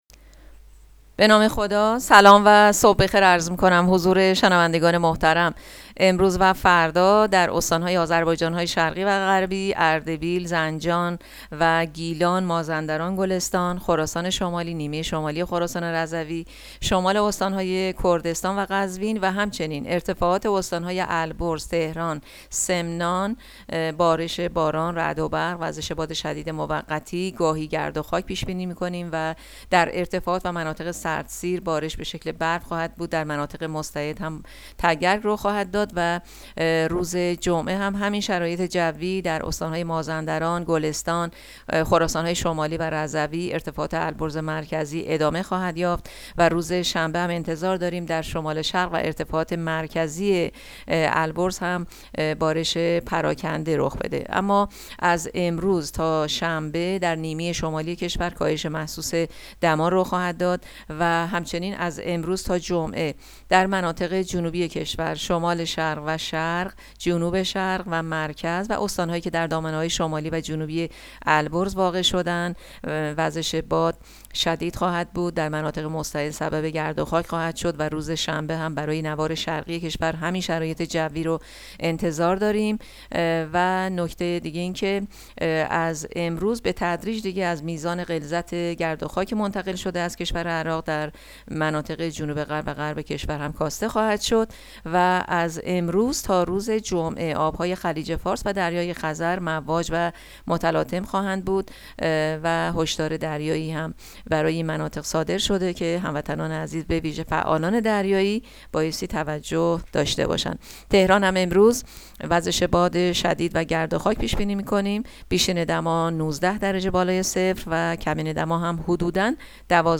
گزارش رادیو اینترنتی پایگاه‌ خبری از آخرین وضعیت آب‌وهوای ۲۷ فروردین؛